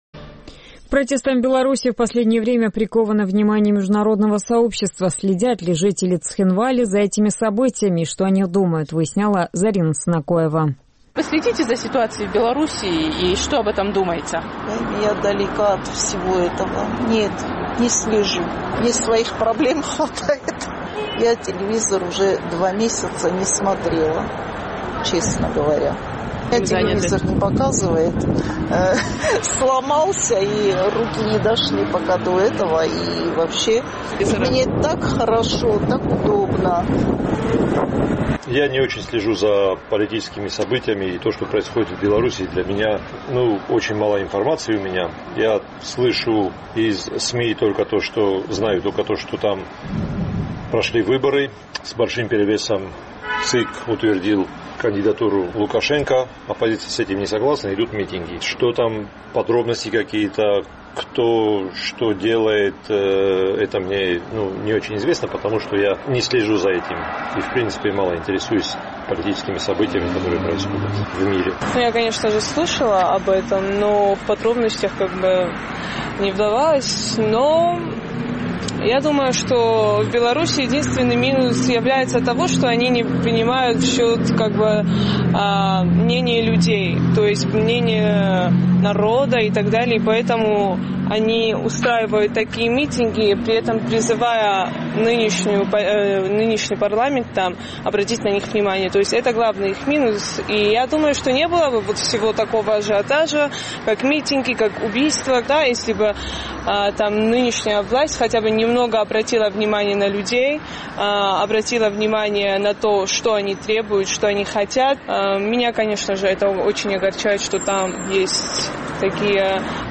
Следят ли в Цхинвале за протестами в Белоруссии и что об этом думают местные жители? Наш традиционный опрос.